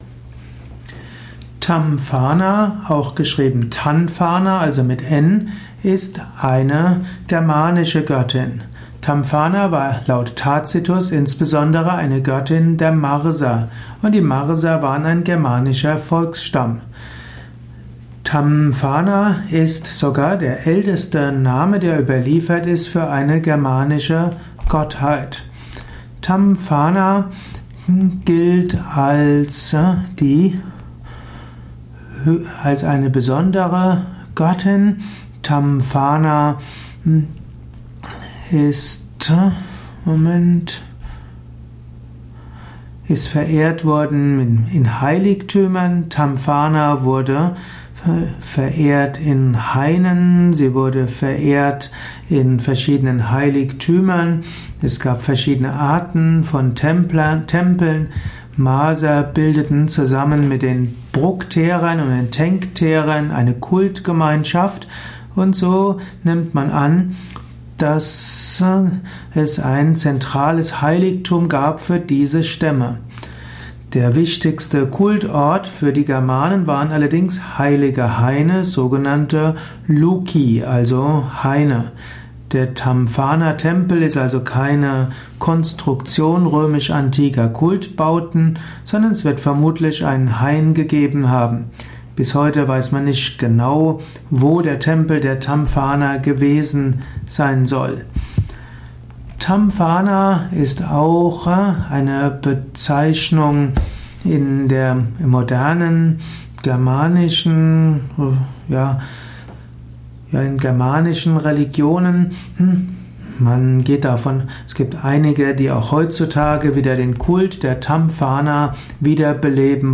Ein Audio Vortrag über Tamfana, einer italischen Göttin. Ausführungen über die Stellung von Tamfana in der italischen Mythologie, im italischen Pantheon.
Dies ist die Tonspur eines Videos, zu finden im Yoga Wiki.